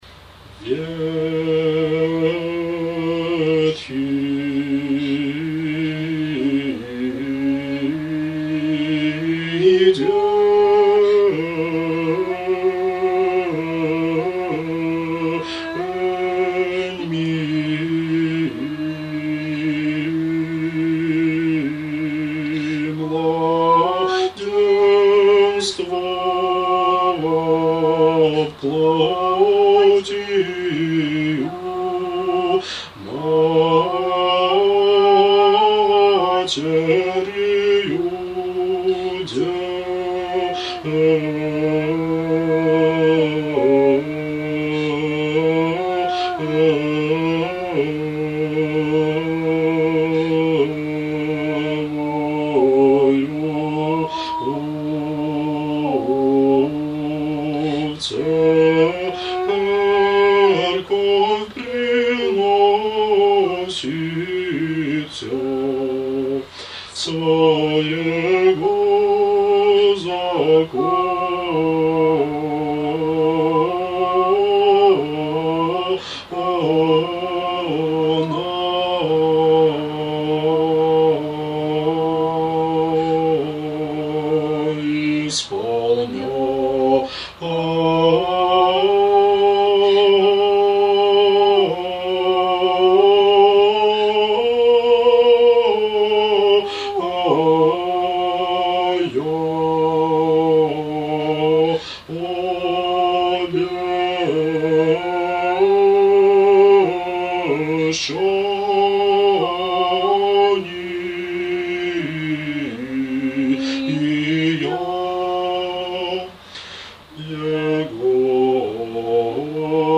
Сретение. На литии И ныне, глас 5. Большой знаменный распев